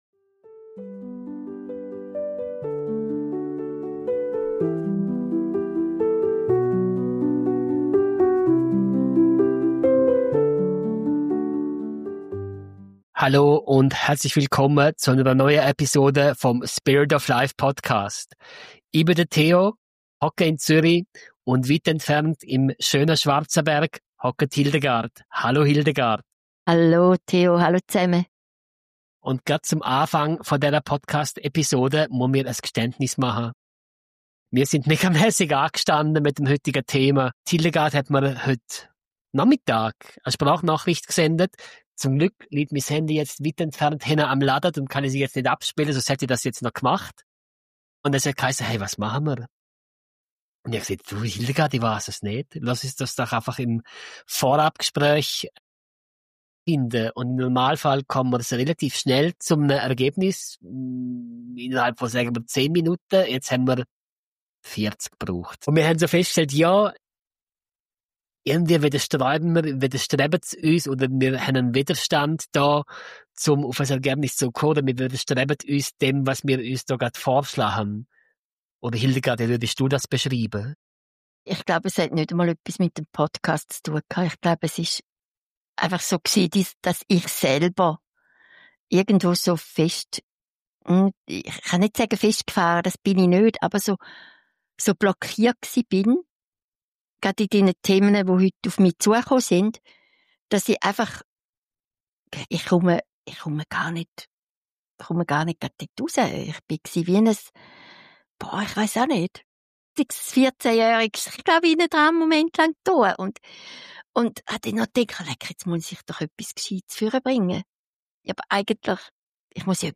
Vom Sprung ins Vertrauen über das Aufschieben unangenehmer Aufgaben bis hin zu grossen Lebensveränderungen: Es geht darum, wie wir blockierte Energie wieder in Bewegung bringen können. Ein authentisches Gespräch über Vertrauen, Präsenz und den liebevollen Umgang mit sich selbst, wenn der Kopf laut ist und das Herz leise ruft.